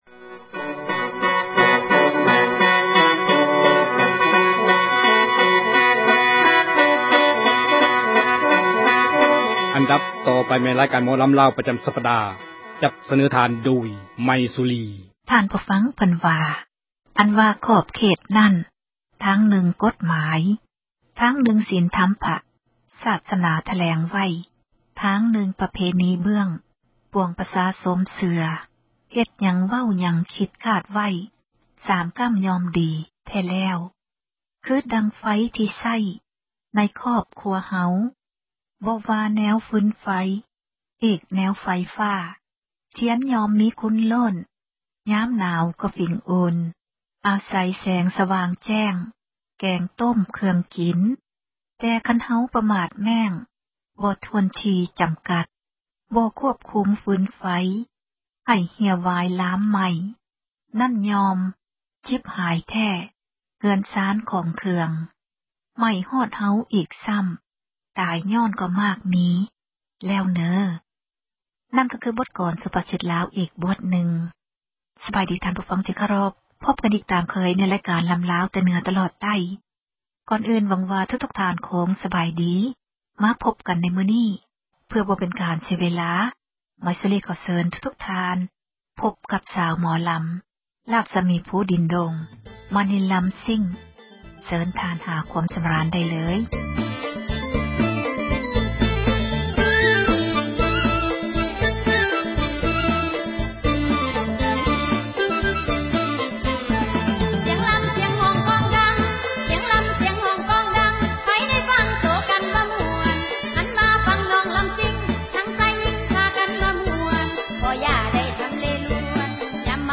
ຣາຍການ ໝໍລໍາ ລາວ ປະຈໍາ ສັປດາ.